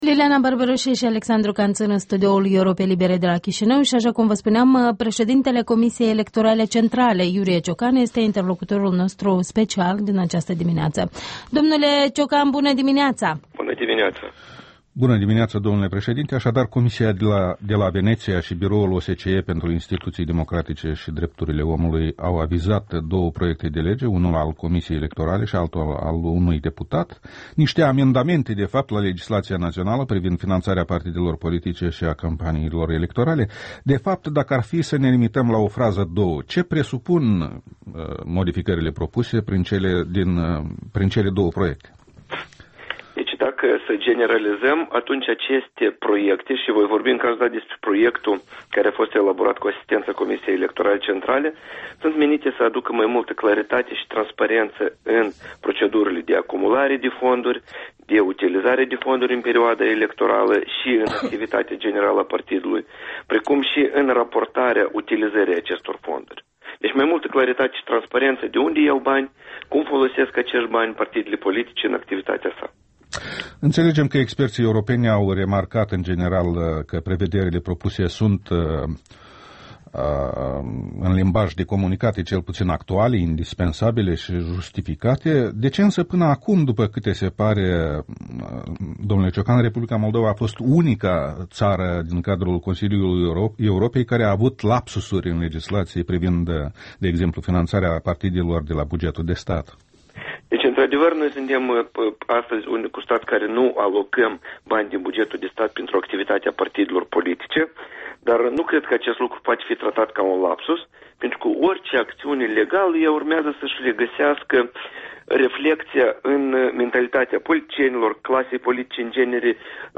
Interviul dimineții: cu Iurie Ciocan (CEC)